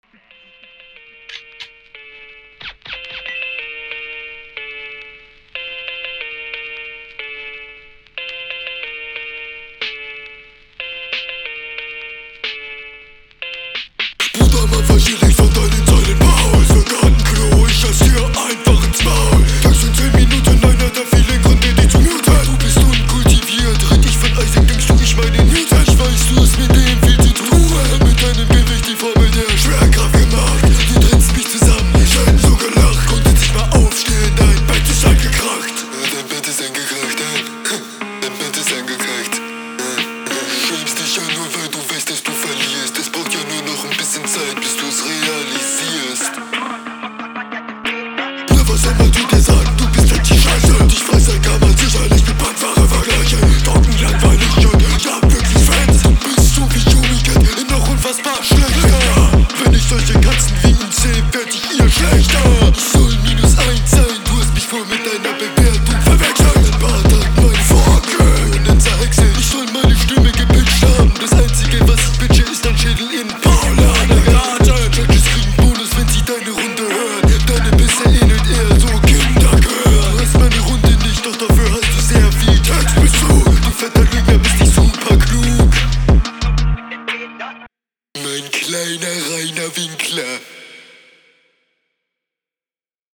Flow: Es ist größtenteils im Takt.